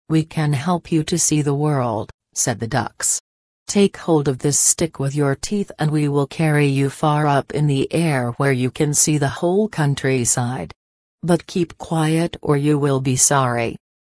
به همراه فایل صوتی تلفظ انگلیسی